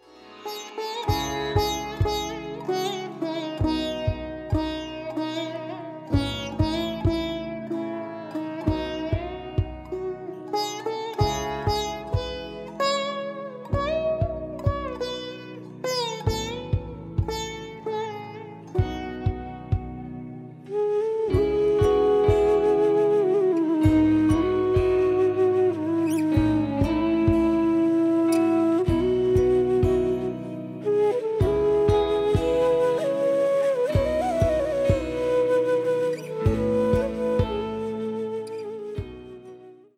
melodious instrumental cover
Category: Instrumental Ringtones